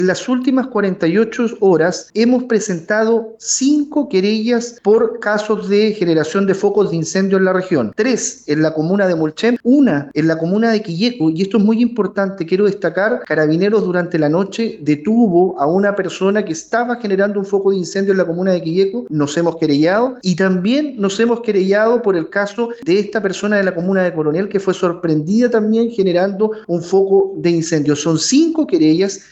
En conversación con Radio Bío Bío en Concepción, el delegado presidencial, Eduardo Pacheco, confirmó que han presentado en cinco querellas por generar focos de incendios forestales: tres en Mulchén, una en Quillota y otro Coronel.